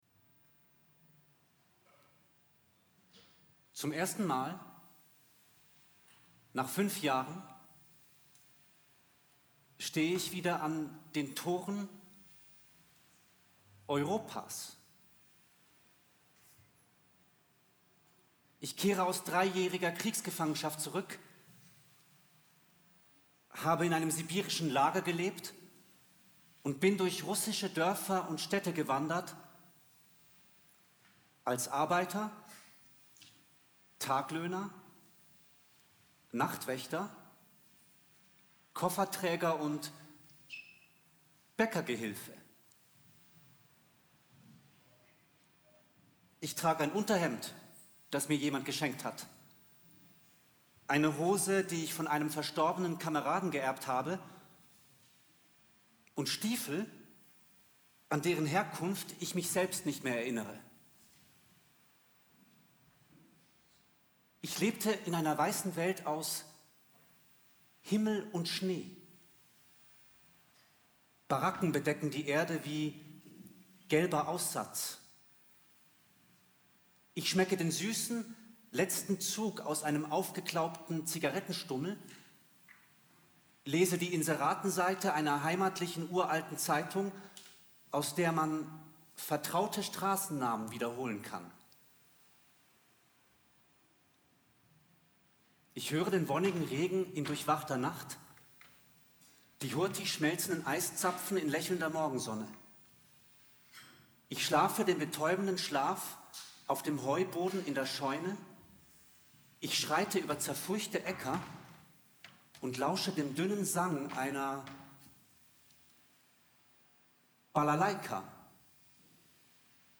Eine Hybridoperette mit der Musicbanda Franui (2024)